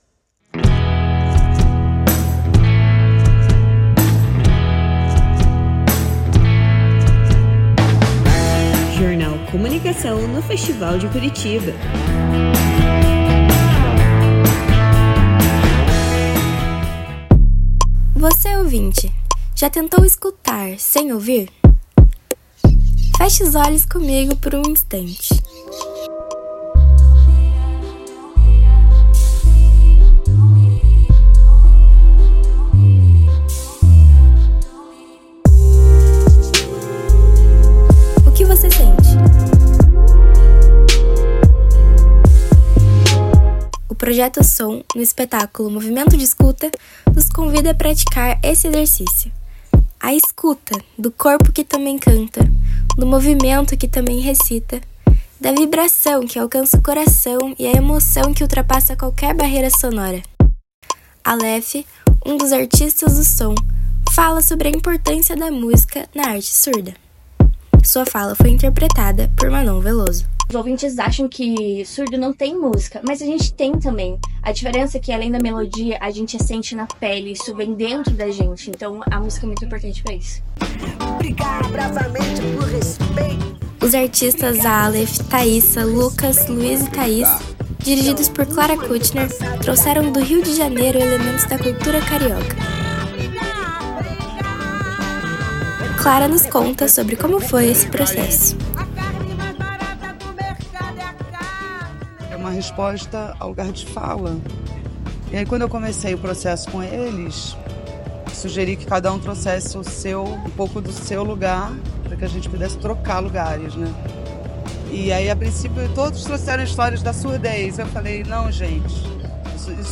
Tempo de reportagem: 3’20”